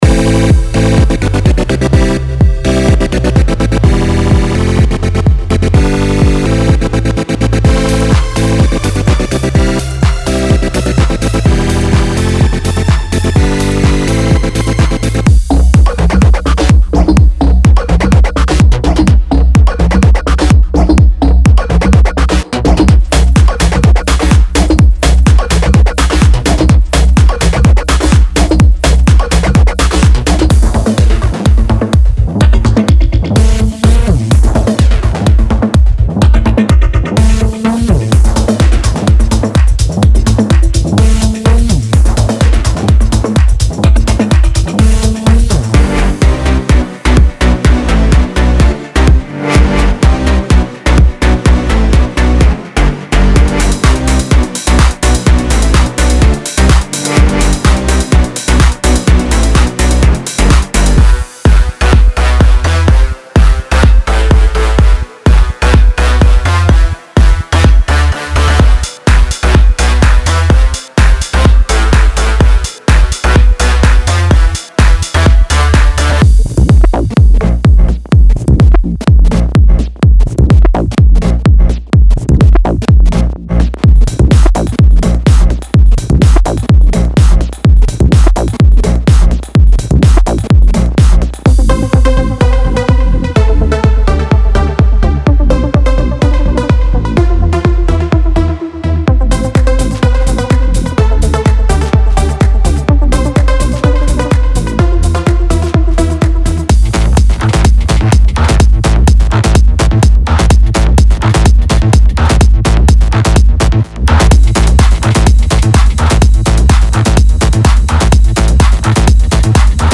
126 BPM